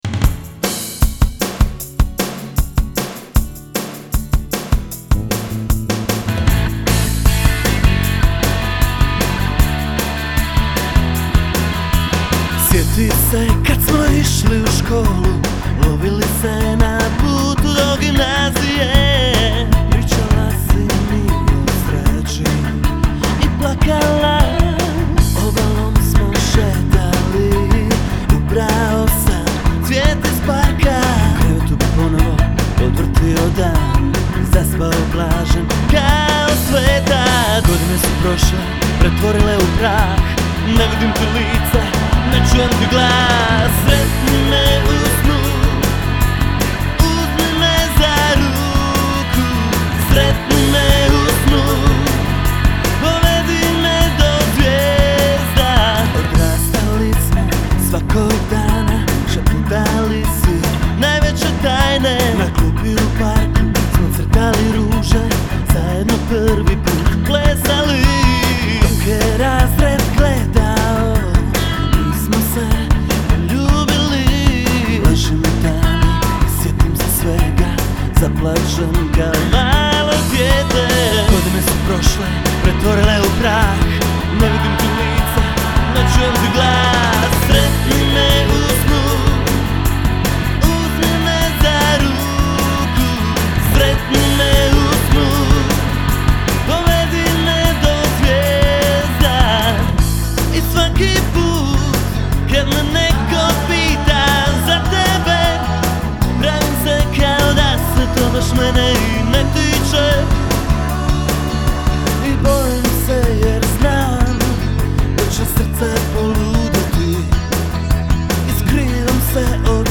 Rock - Pop